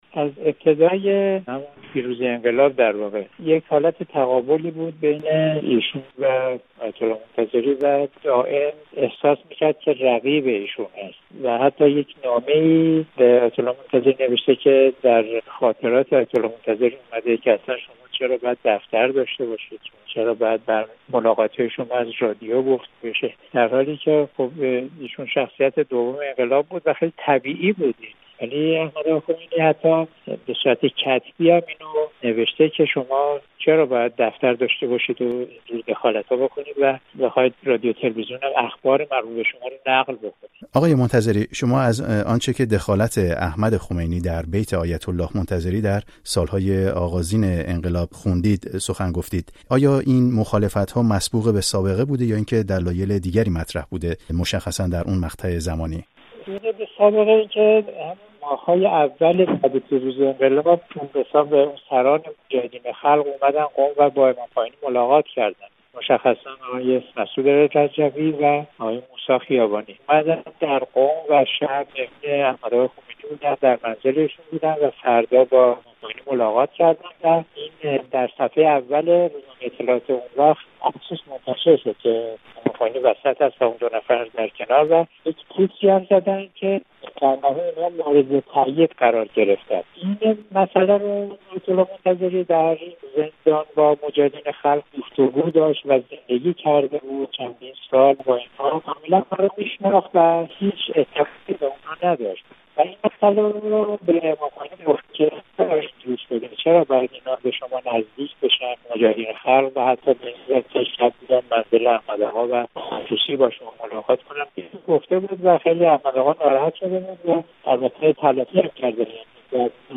در گفت‌وگو با احمد منتظری جزئیات بیشتر این موضوع را جویا شدم.